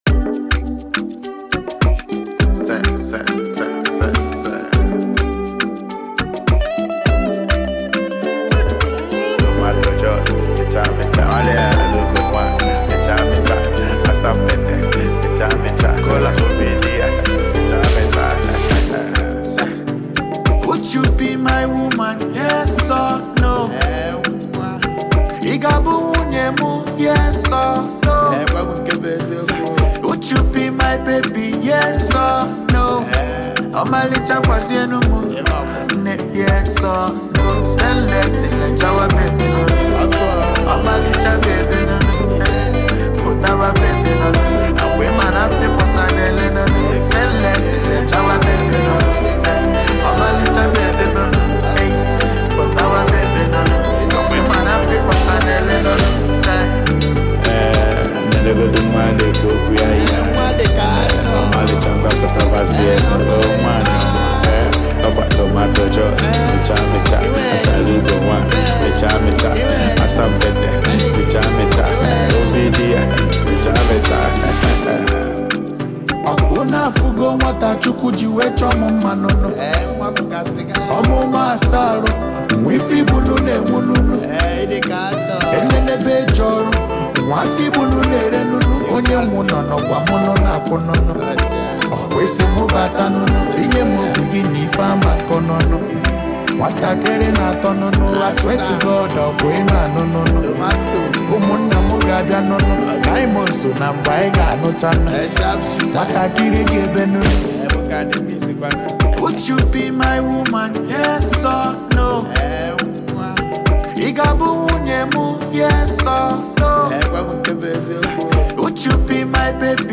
melodic serenade